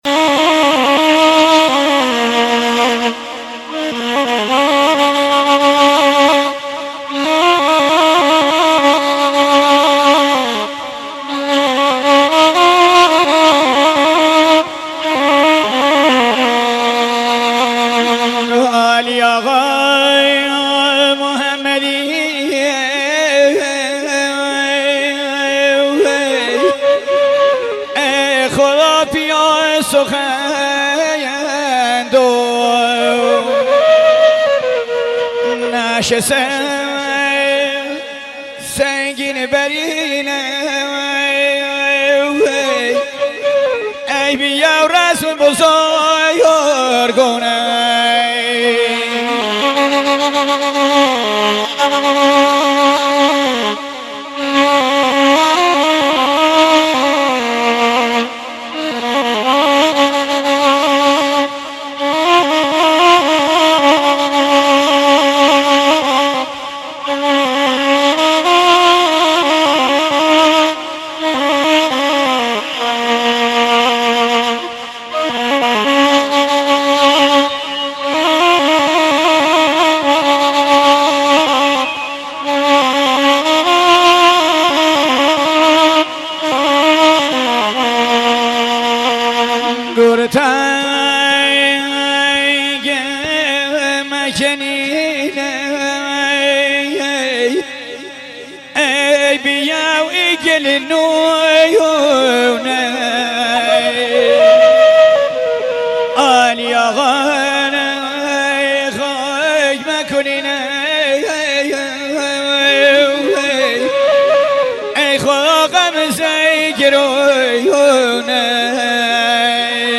عزاداری و سوگواری لری بختیاری
همراه با نوای نی